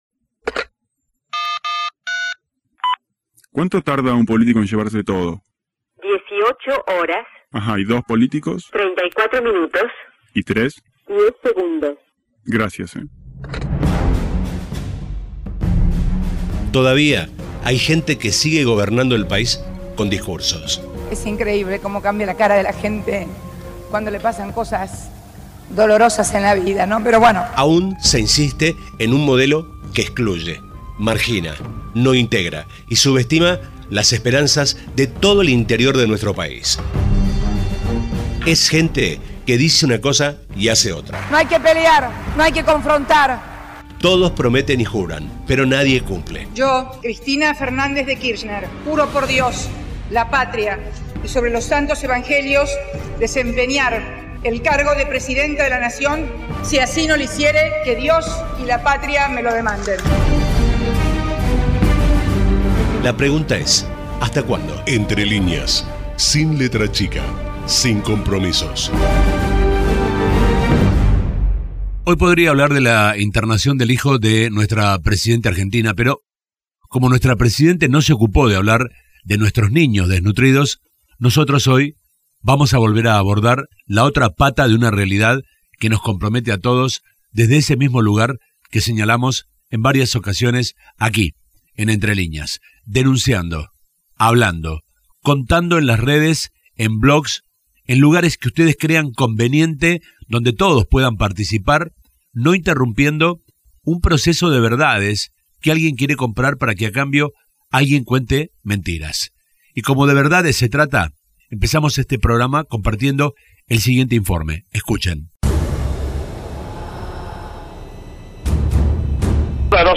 Voz de la narración